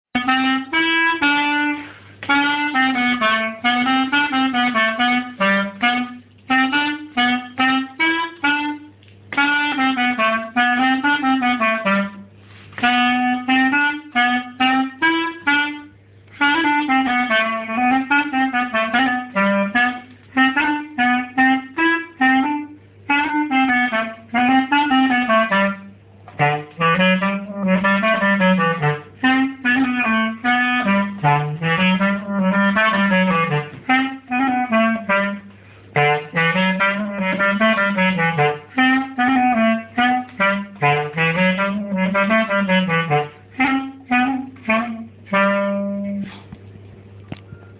clarinette-polka_lent.mp3